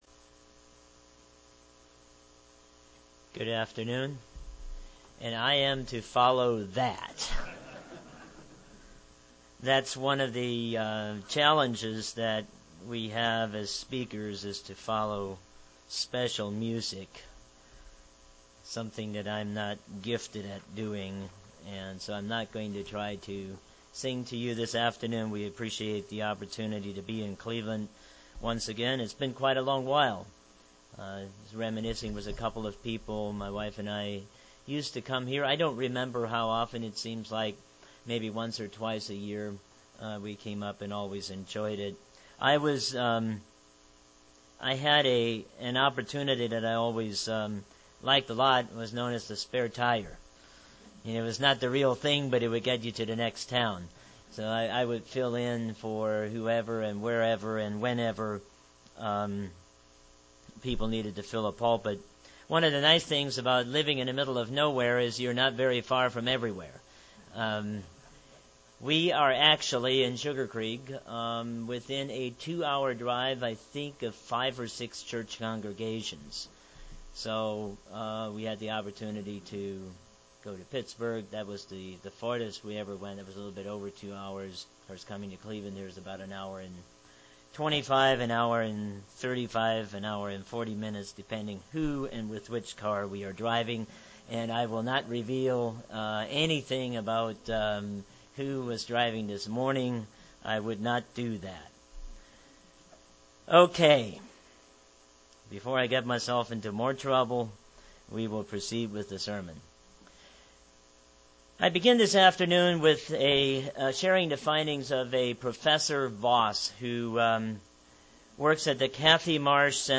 Given in Cleveland, OH
UCG Sermon Studying the bible?